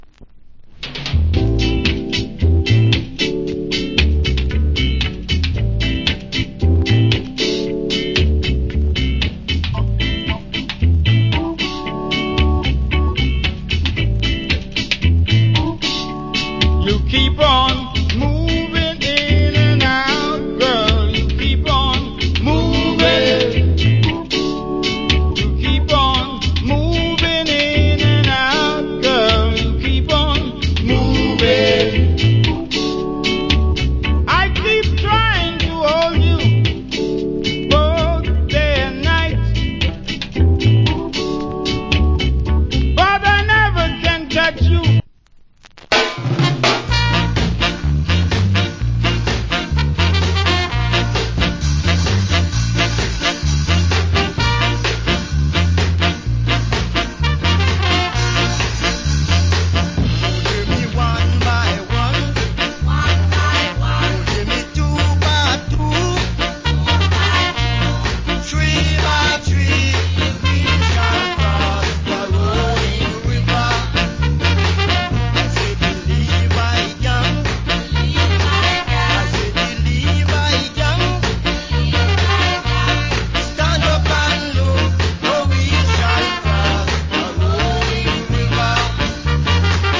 Killer Reggae Vocal.